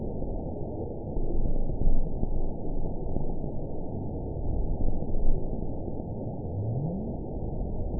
event 921011 date 04/24/24 time 01:40:22 GMT (1 year, 1 month ago) score 9.15 location TSS-AB05 detected by nrw target species NRW annotations +NRW Spectrogram: Frequency (kHz) vs. Time (s) audio not available .wav